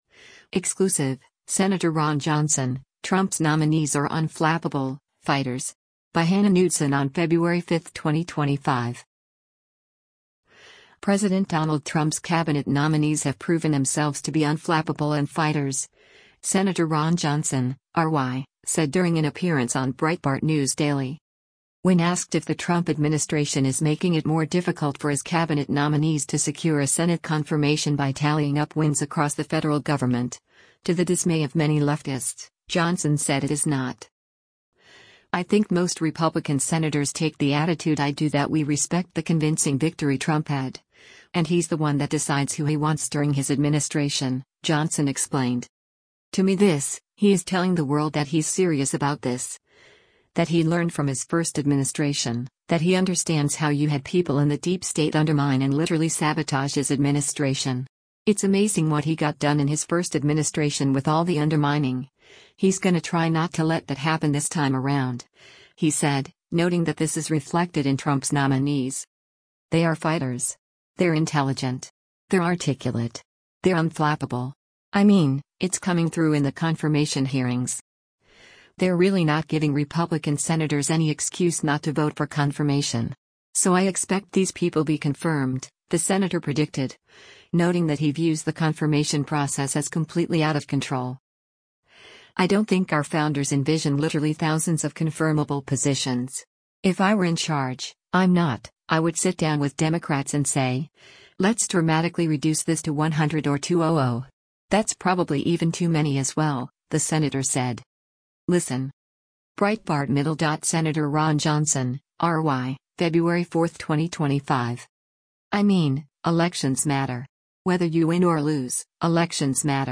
President Donald Trump’s Cabinet nominees have proven themselves to be “unflappable” and “fighters,” Sen. Ron Johnson (R-WI) said during an appearance on Breitbart News Daily.
Breitbart News Daily airs on SiriusXM Patriot 125 from 6:00 a.m. to 9:00 a.m. Eastern.